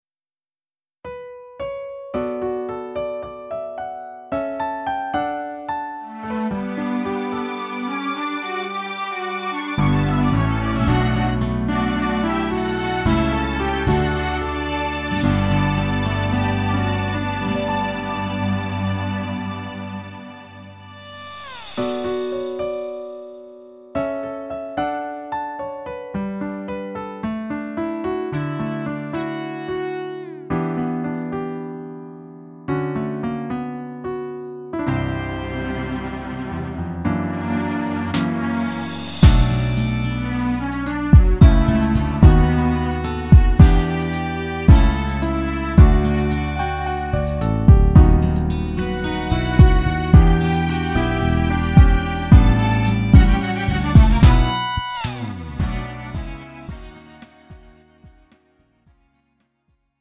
음정 원키 3:46
장르 가요 구분 Lite MR